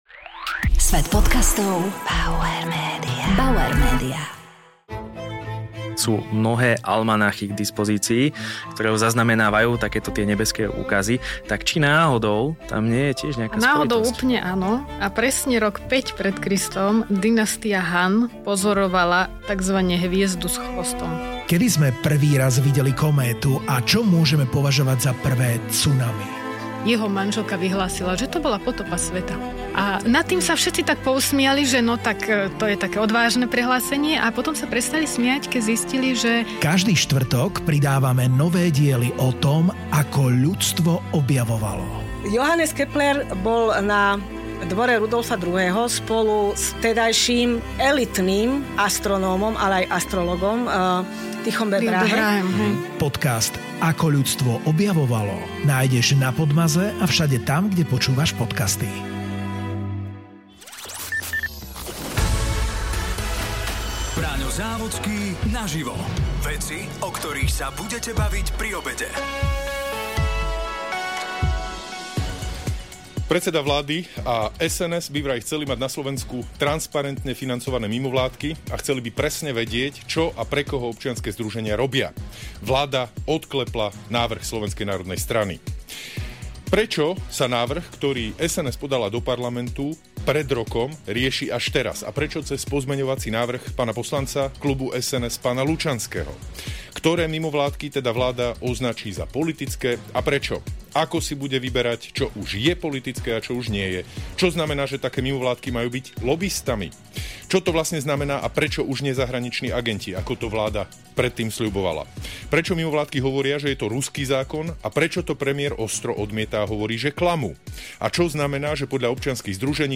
Pobavíme sa s predstaviteľmi mimovládnych organizácii.